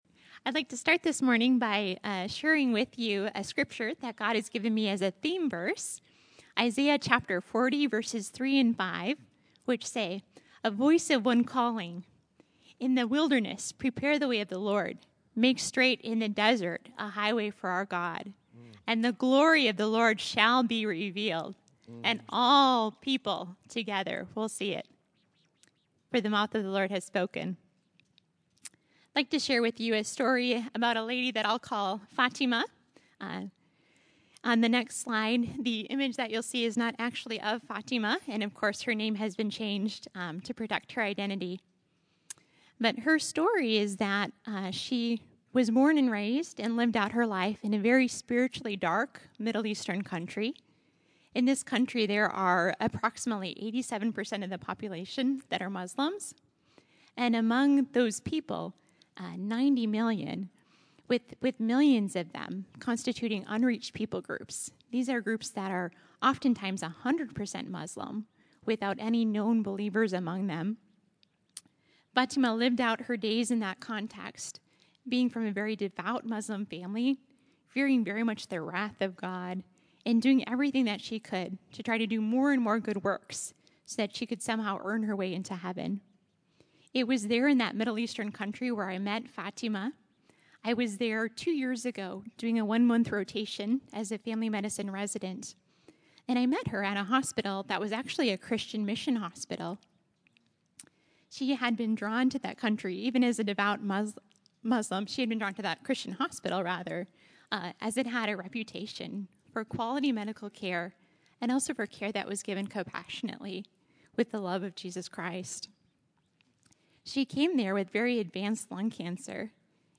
February 02, 2014      Category: Testimonies      |      Location: El Dorado